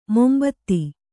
♪ mombatti